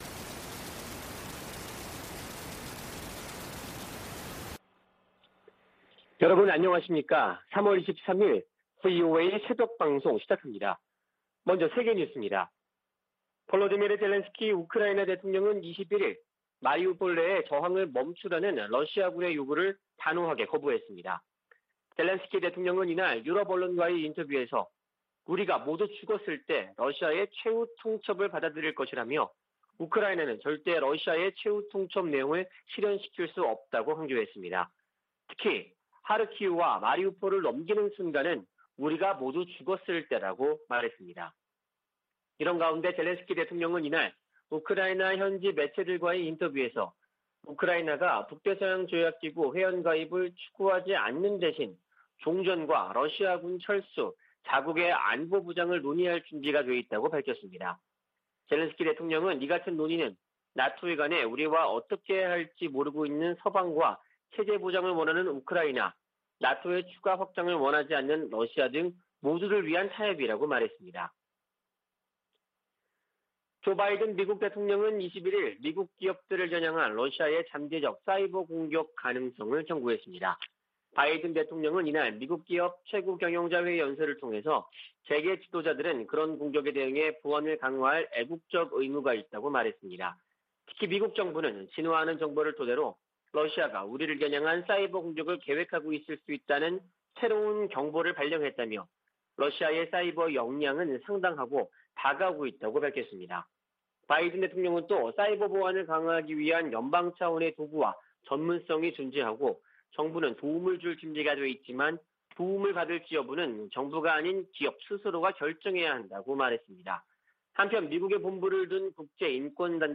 VOA 한국어 '출발 뉴스 쇼', 2022년 3월 23일 방송입니다. 북한이 연일 미한 연합훈련을 비난하는데 대해, 이는 동맹의 준비태세를 보장하는 주요 방법이라고 미 국방부가 밝혔습니다. 윤석열 한국 대통령 당선인이 북한의 최근 서해상 방사포 발사를 9.19 남북군사합의 위반이라고 말한데 대해 한국 국방부가 합의 위반은 아니라고 밝혀 논란을 빚고 있습니다. 미 의회에서 북한의 사이버 위협에 대응하기 위한 입법 움직임이 활발합니다.